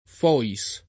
Fois [ f 0 i S ; fr. f U# ]